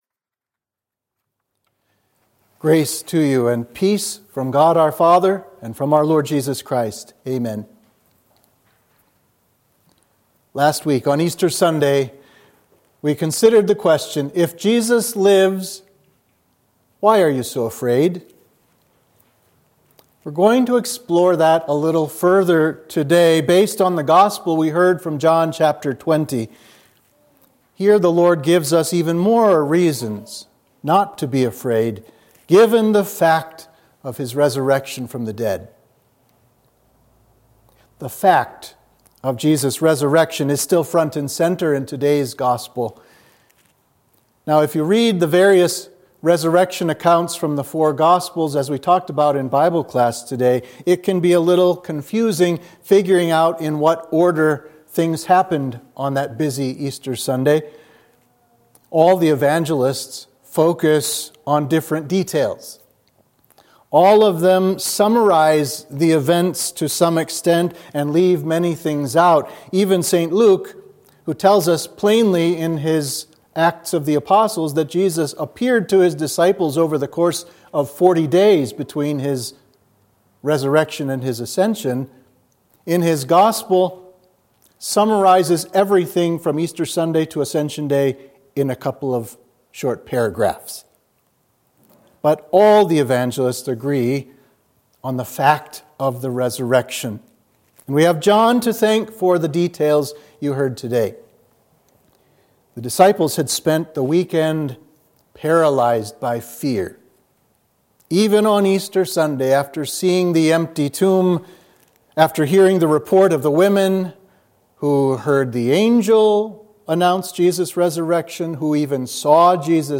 Sermon for Easter 1